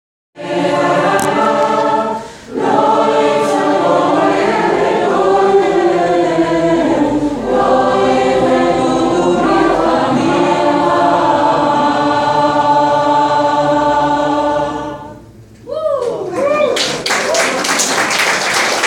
Hier hatten wir einen Raum von 50 Leuten, die zu Beginn (vermutlich) weder die Texte noch die Melodien kannten, und die am Ende mehrstimmig oder im Kanon verschiedene Lieder sangen.
(z.B. Katherina Mome (bulgarisches Volkslied), Loy Yisa Goy (Hebräisches Lied), Osi boku (afrikan.